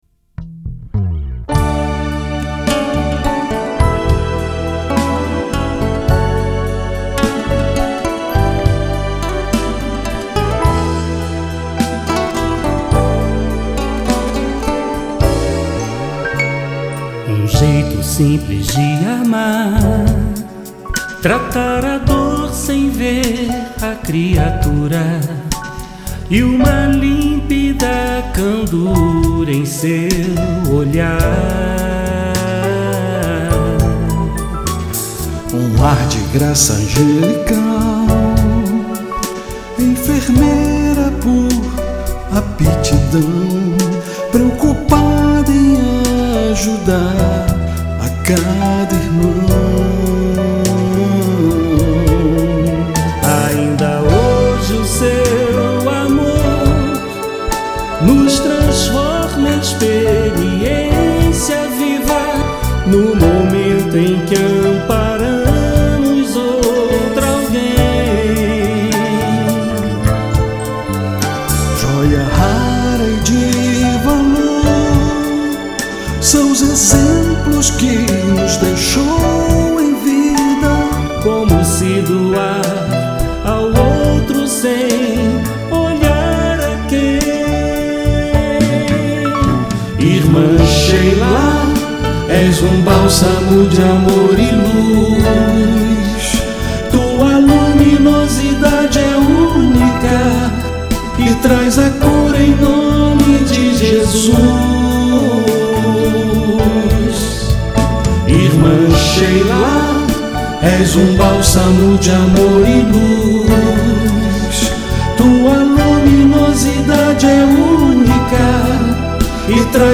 EstiloInstrumental